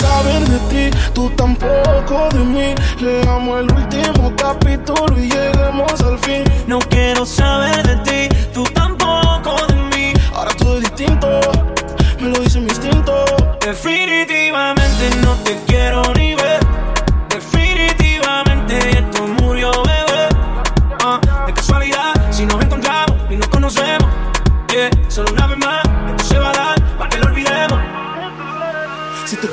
Genere: pop latin, urban latin, reggaeton, remix